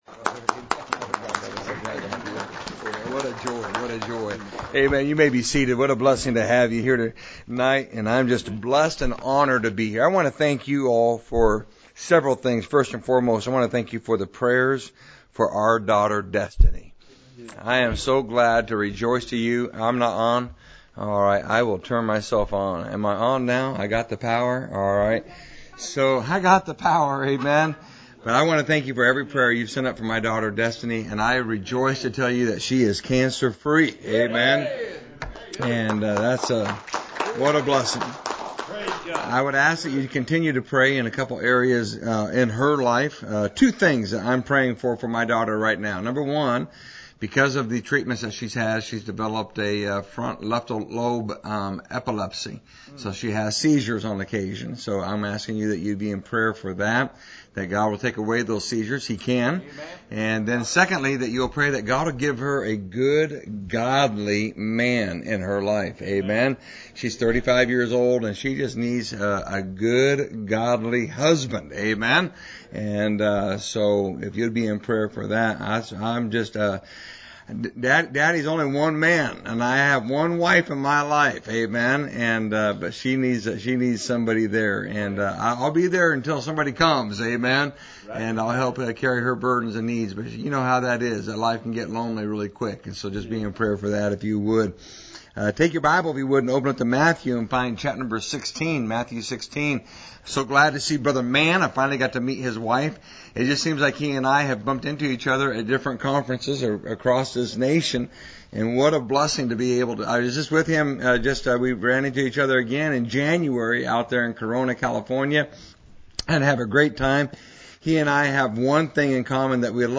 Missions Conference